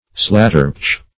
Search Result for " slatterpouch" : The Collaborative International Dictionary of English v.0.48: Slatterpouch \Slat"ter*pouch`\, n. A dance or game played by boys, requiring active exercise.